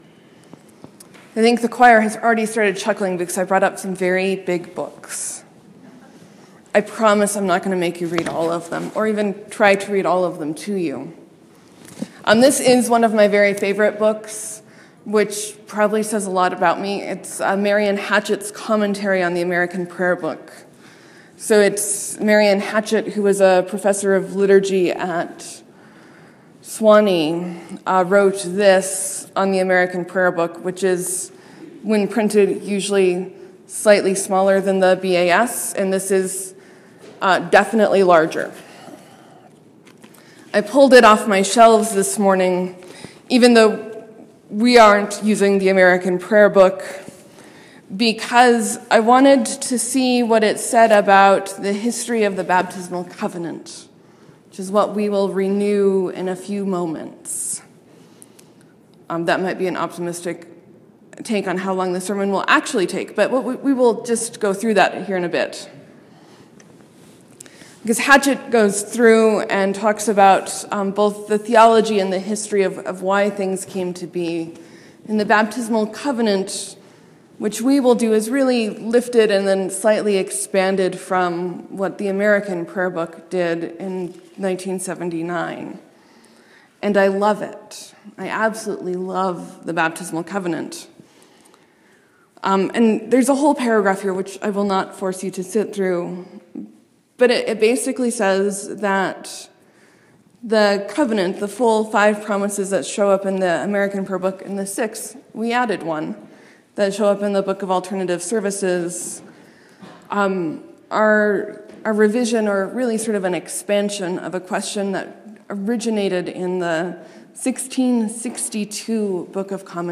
Sermon: We back Peter’s story up a few verses and explore being out of place.